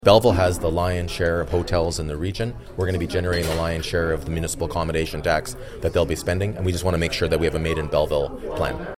Mayor Mitch Panciuk tells Quinte News council wants to see how that money will be spent.